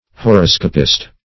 Meaning of horoscopist. horoscopist synonyms, pronunciation, spelling and more from Free Dictionary.